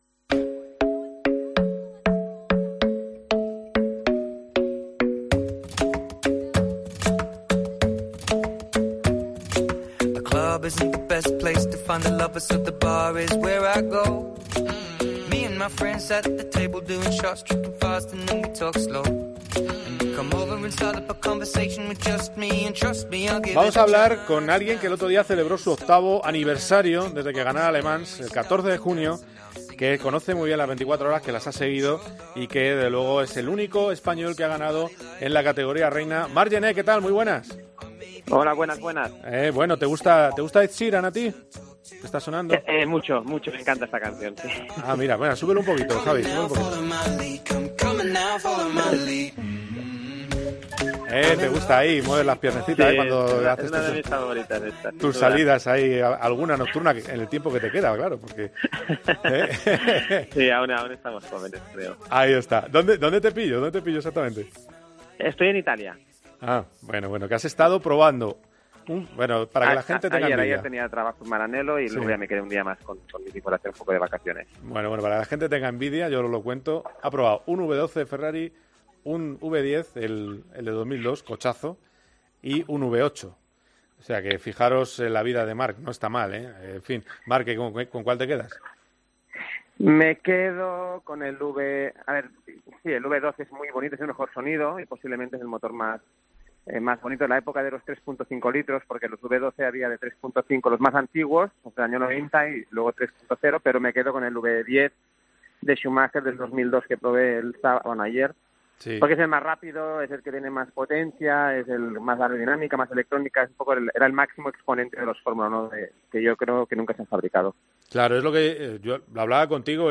AUDIO: Hablamos con el piloto probador de Ferrari y ganador de las 24 Horas de Le Mans sobre la actualidad de la Fórmula 1 y la legendaria prueba....